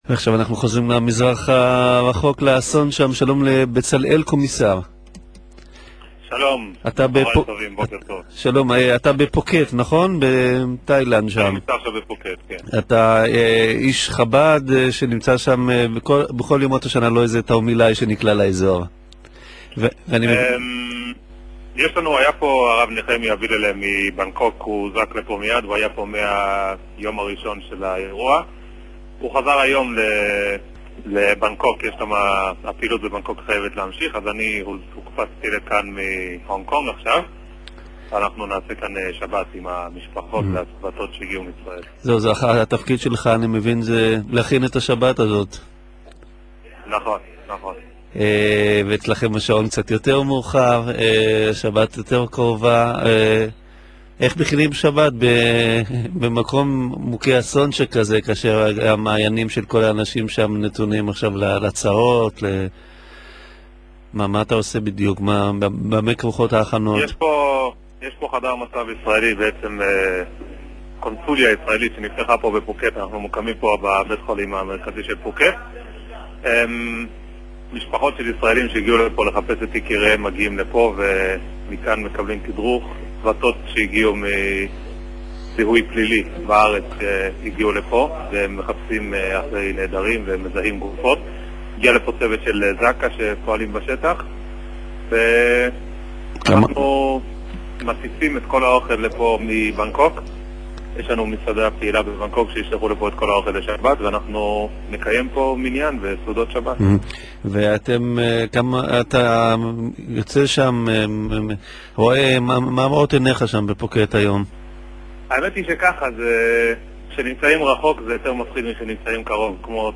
התראיין ביומן הצהריים 'לפי שעה' בערוץ 7